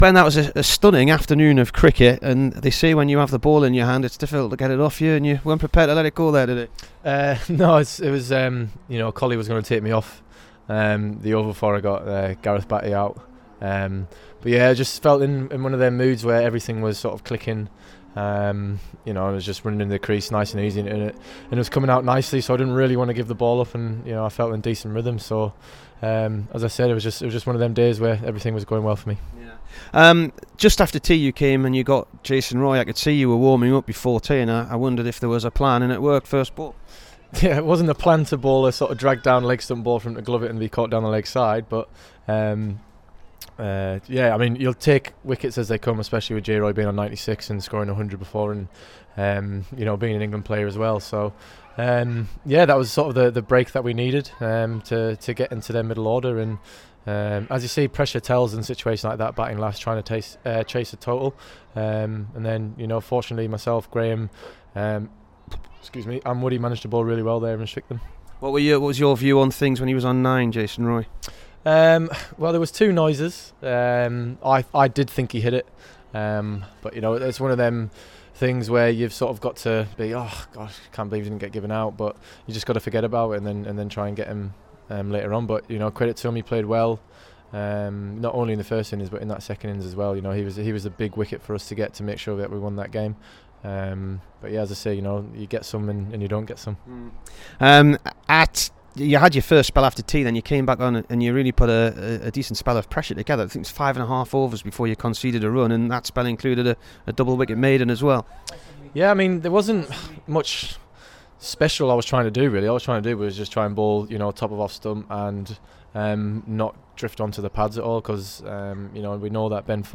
BEN STOKES INT